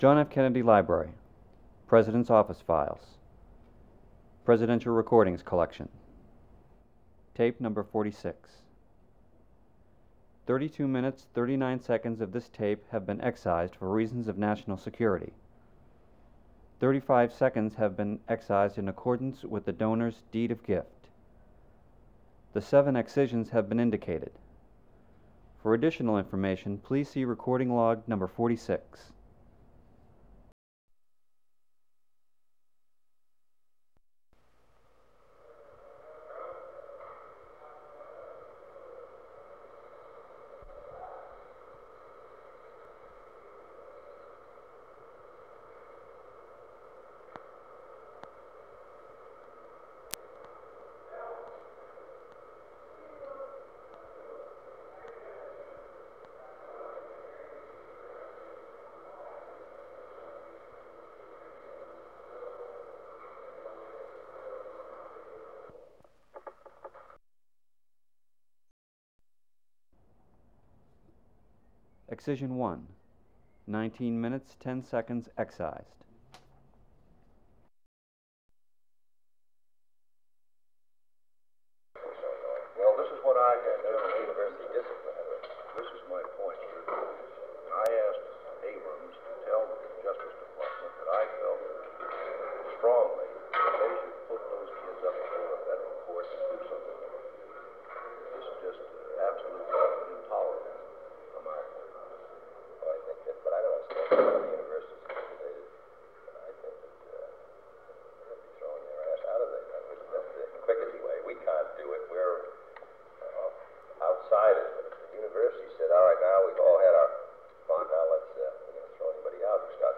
Meeting with Representatives of the U.S. Army
Secret White House Tapes | John F. Kennedy Presidency Meeting with Representatives of the U.S. Army Rewind 10 seconds Play/Pause Fast-forward 10 seconds 0:00 Download audio Previous Meetings: Tape 121/A57.